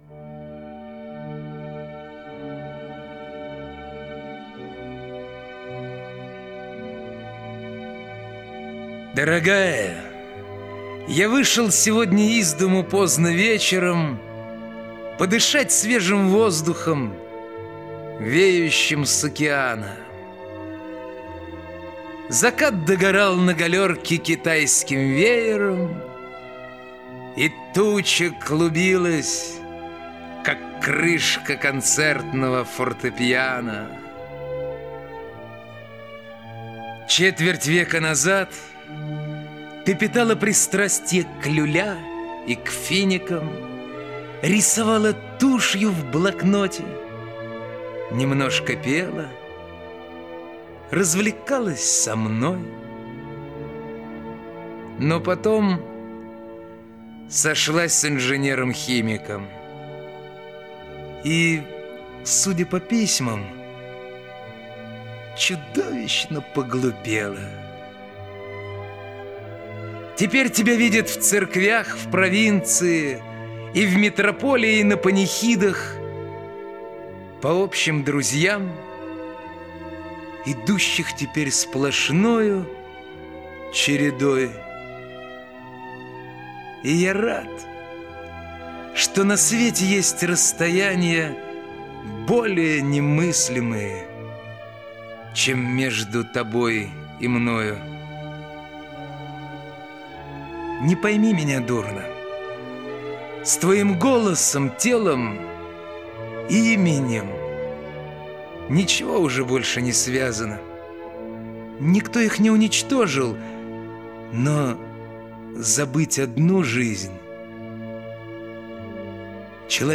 2. «Иосиф Бродский – Дорогая, я вышел сегодня из дому поздно вечером (читает Олег Меньшиков)» /